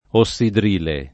ossidrile [ o SS idr & le ] s. m. (chim.)